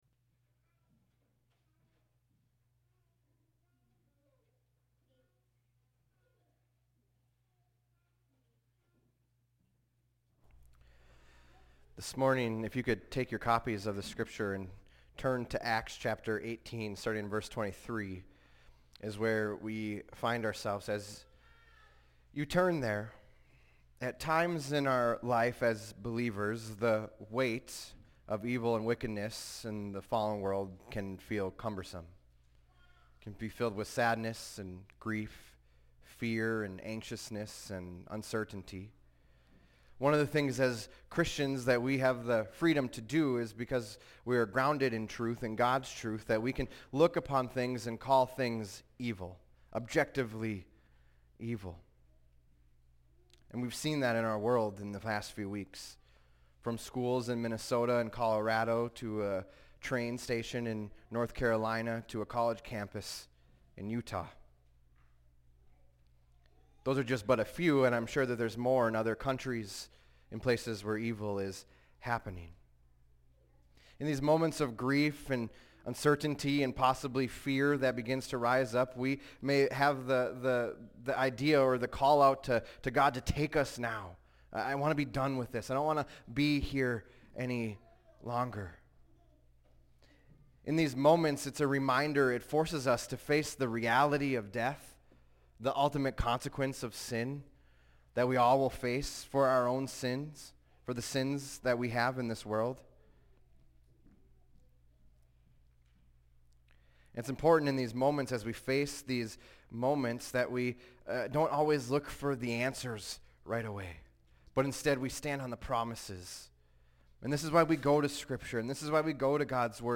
fbc_sermon_091425.mp3